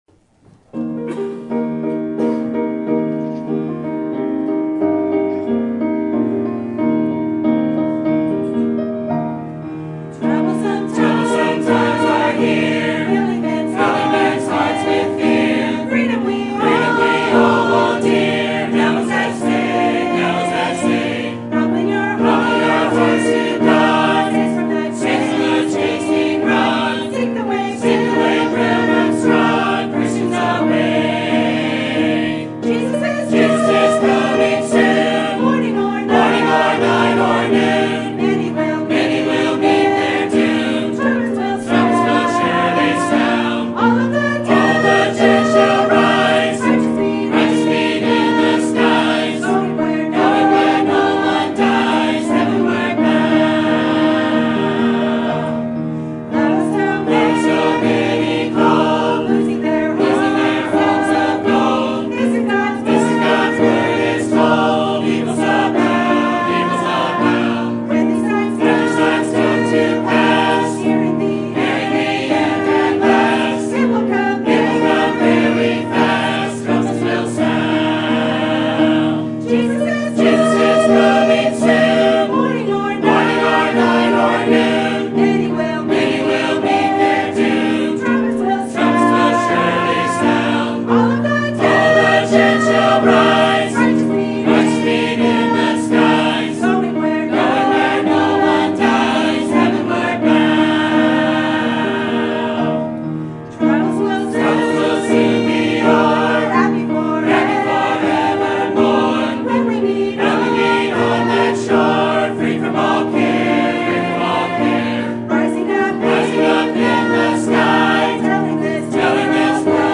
Sermon Date